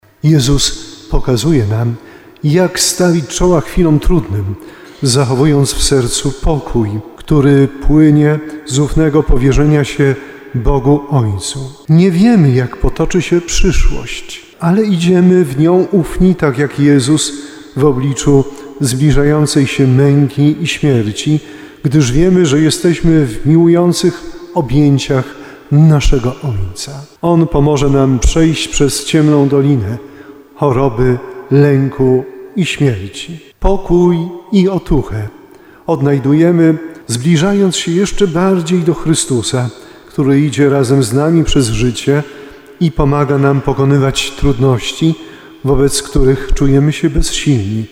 Ordynariusz Diecezji Tarnowskiej przewodniczył Mszy Świętej w bazylice katedralnej w Tarnowie. Msza św. była transmitowana na antenie Radia RDN Małopolska i RDN Nowy Sącz.